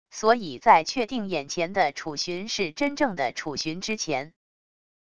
所以在确定眼前的楚旬是真正的楚旬之前wav音频生成系统WAV Audio Player